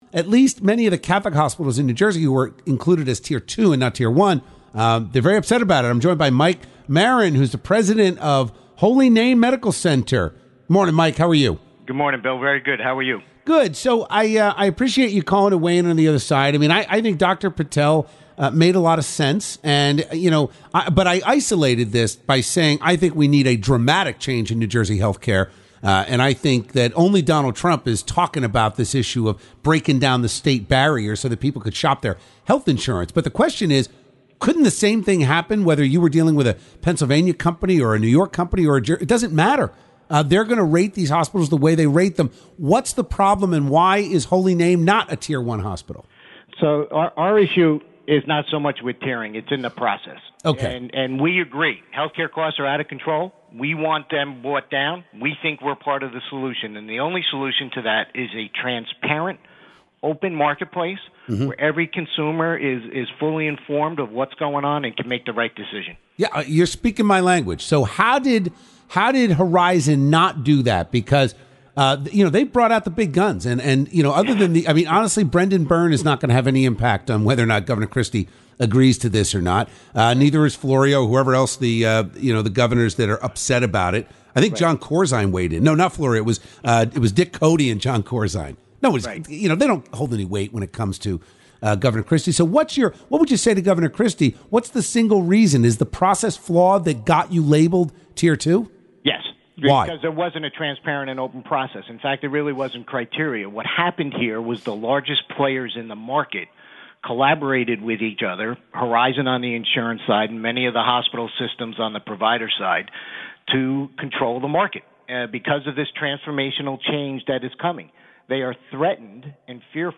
Radio Interviews